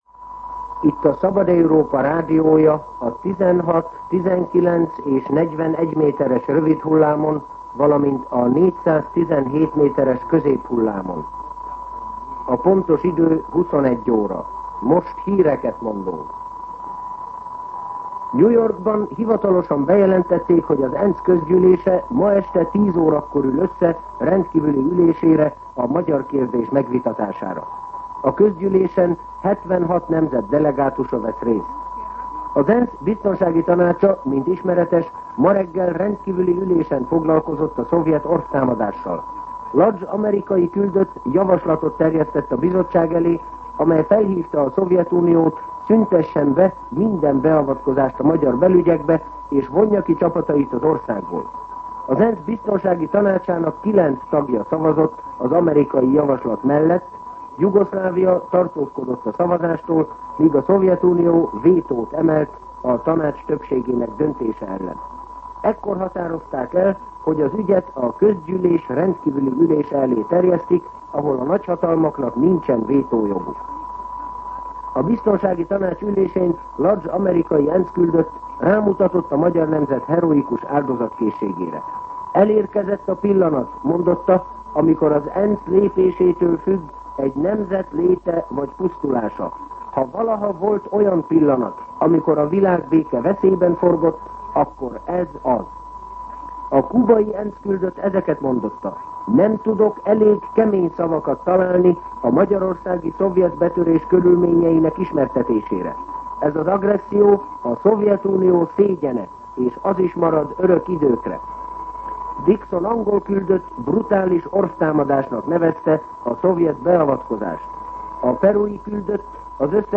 21:00 óra. Hírszolgálat